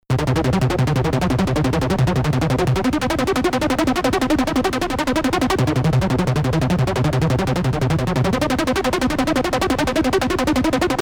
Anhänge keine engelschöre aber was anderes.mp3 keine engelschöre aber was anderes.mp3 430,7 KB · Aufrufe: 309